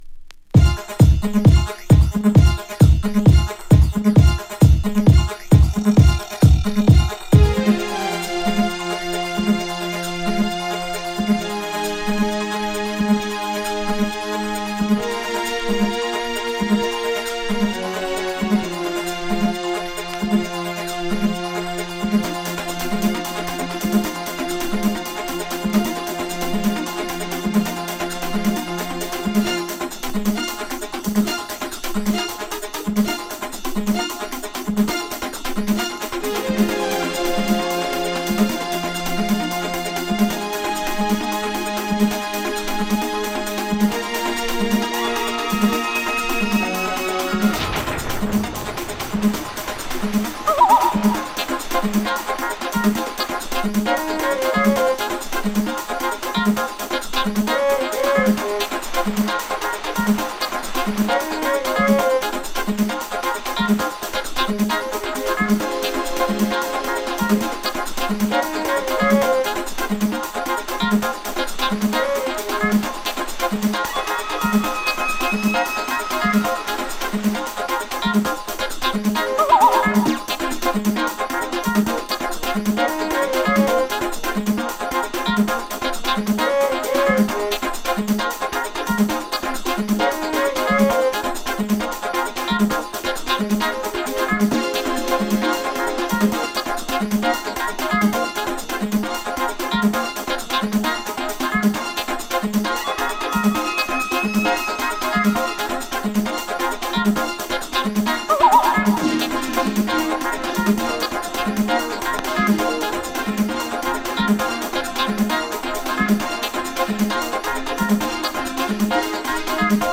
バウンシーな4/4/トラックを下敷きに、コズミックなフレーズと壮大でドラマチックなストリングスが一体となる